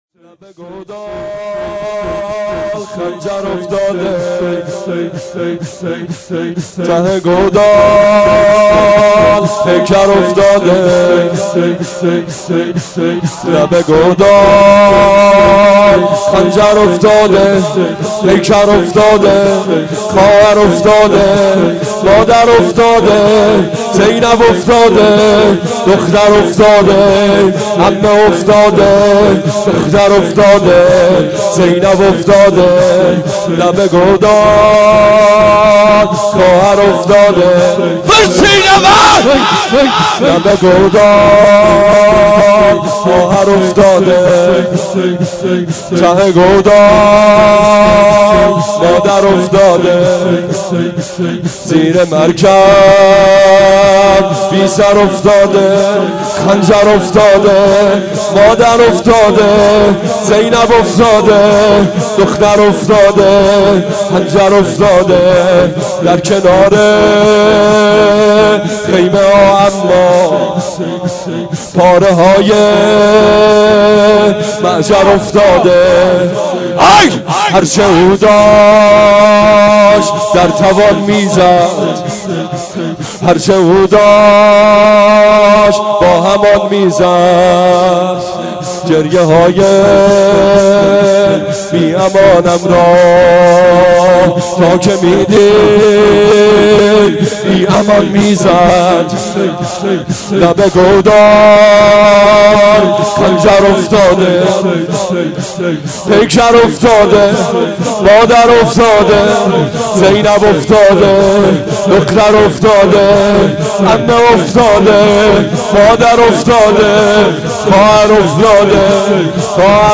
هیئت عاشورائیان زنجان
شور | لب گودال
سینه زنی
مداحی